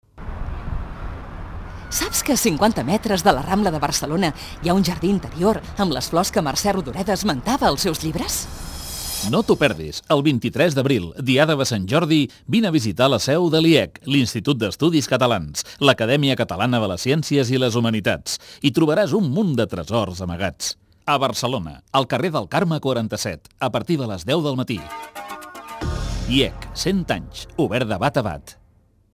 Dos dels sis models de falques emeses per Catalunya Ràdio:
Falca_SantJordi_2.mp3